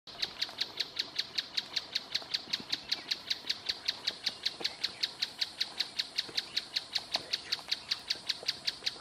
Churrín Plomizo (Scytalopus speluncae)
Nombre en inglés: Mouse-colored Tapaculo
Fase de la vida: Adulto
Localidad o área protegida: Floresta Nacional de São Francisco de Paula
Condición: Silvestre
Certeza: Vocalización Grabada
Scytalopus-speluncae.mp3